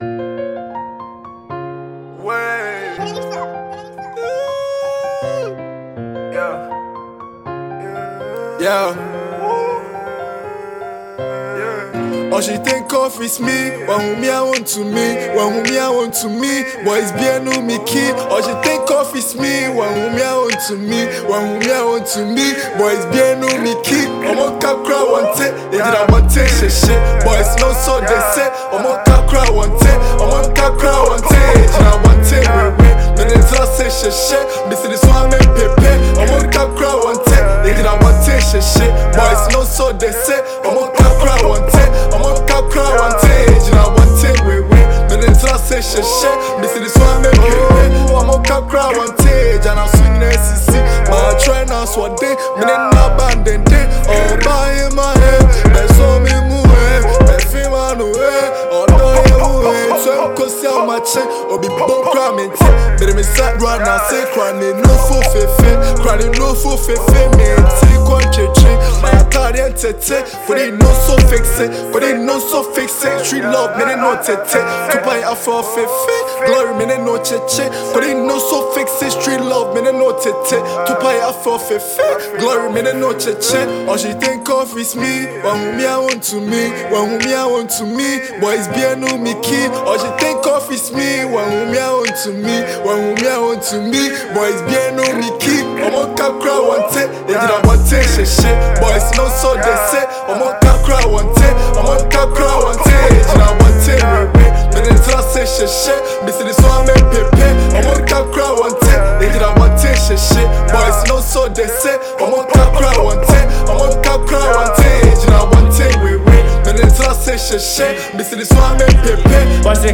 Ghana Music Music
asakaa song